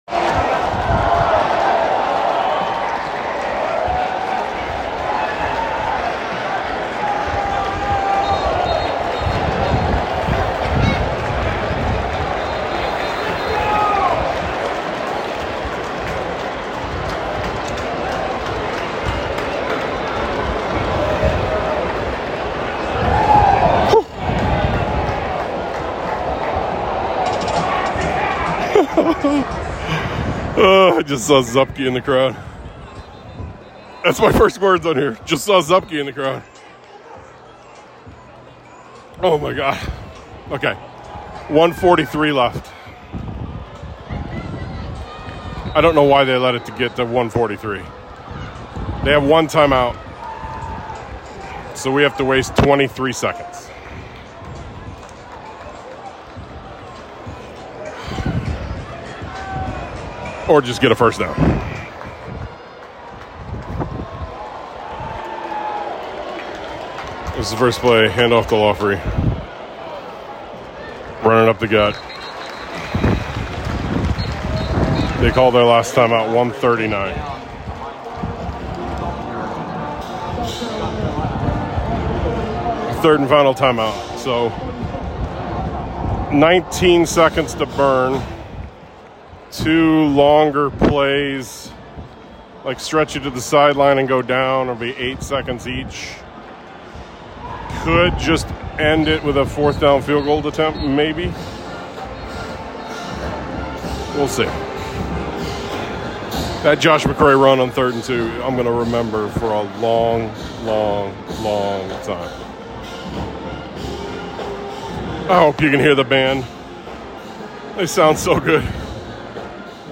I'm still trying to figure out where this one ranks among greatest wins of my lifetime. So here I am wandering around for 24 minutes trying to talk about it.